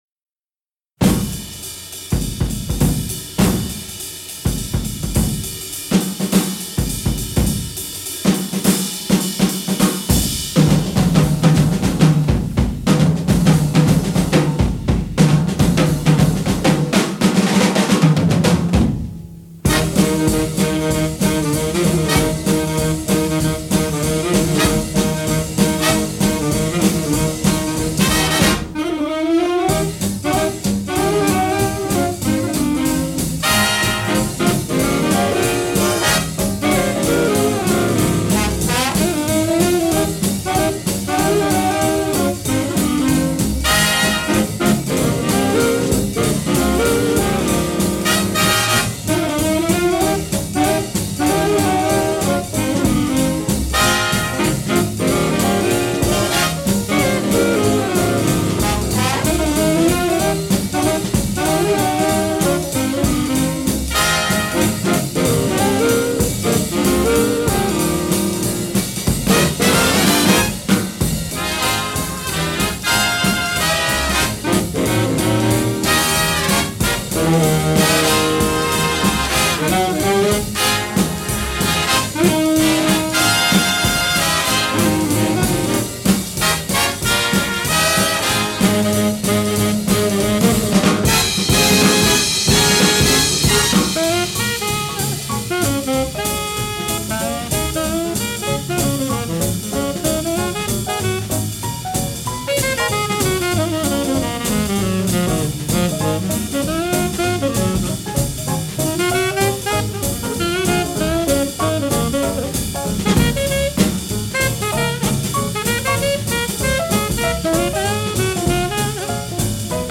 You want to get up and dance.